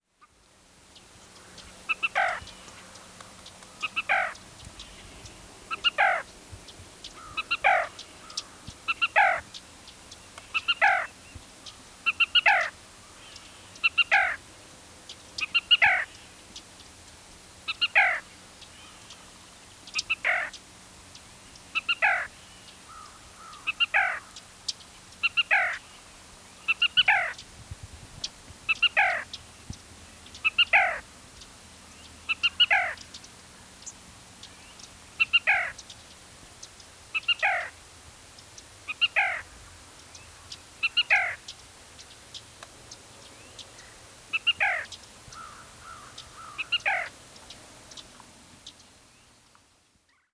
THE HOLY GRAIL BIRD, THE ELUSIVE BLACK RAIL!
Despite this large range, it is a very difficult bird to see. I was lucky enough to photograph one and get video and recordings.
Black-Rail-Costal-Prairie-Trail-Everglades-Natiional-Park3.wav